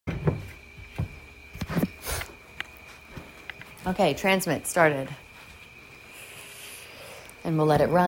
outside of Hawkinsville on the Ocmulgee River